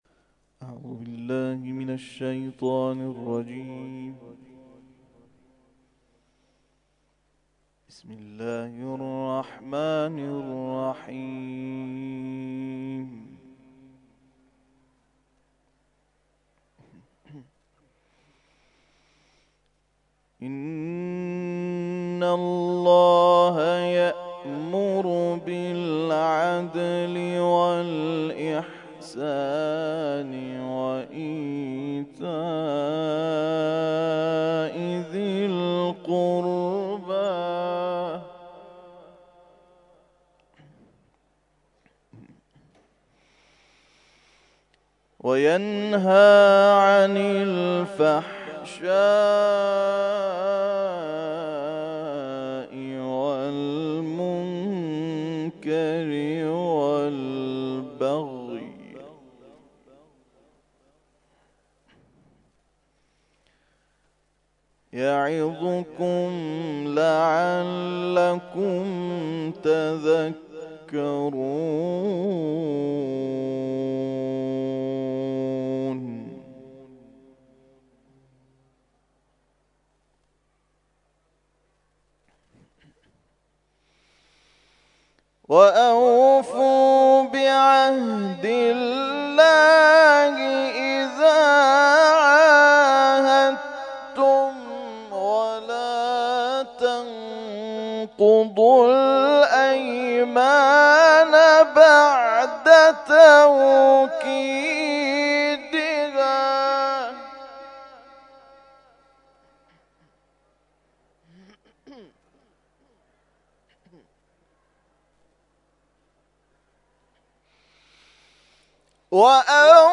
تلاوت ظهر